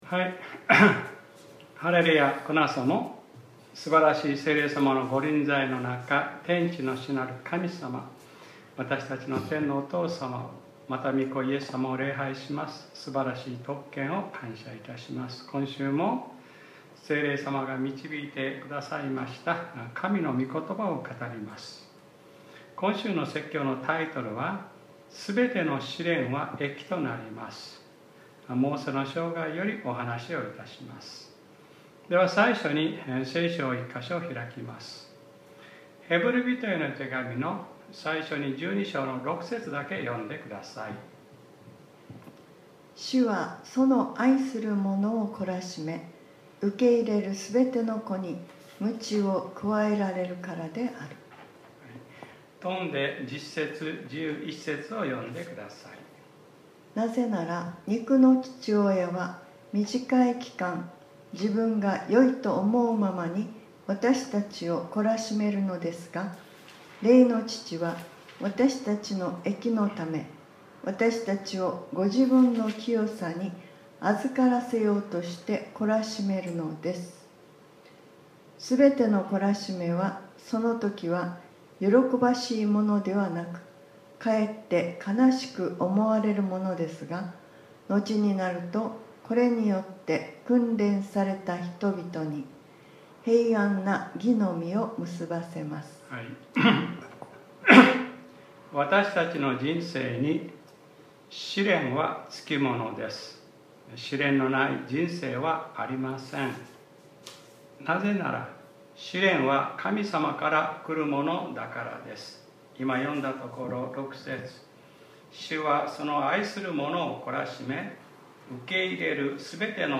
2025年08月03日（日）礼拝説教『 すべての試練は益となります 』 | クライストチャーチ久留米教会